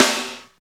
45.08 SNR.wav